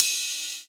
VEC3 Cymbals Ride 25.wav